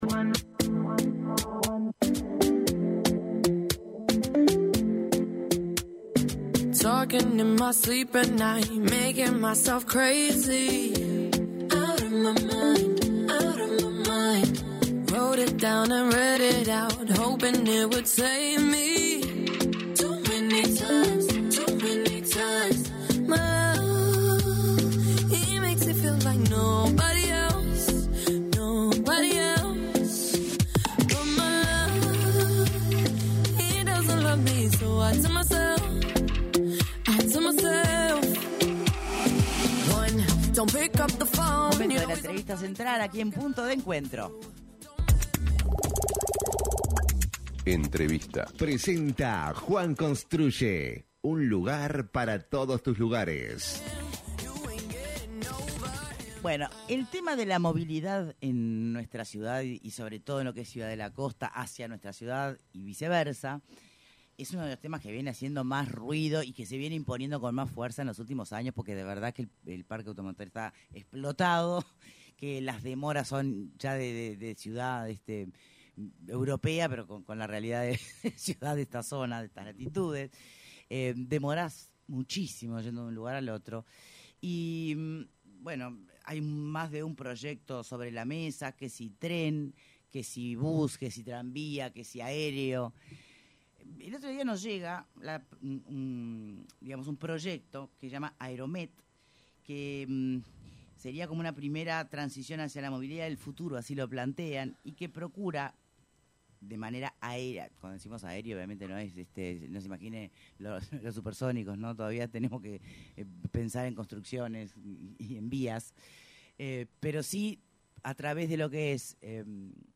Las empresas Berkes, Teyma y Aerom presentaron al gobierno una iniciativa privada Aeromet, un sistema de transporte ferroviario elevado con un primer carril que iría de Tres Cruces al Parque Roosevelt. En entrevista con Punto de Encuentro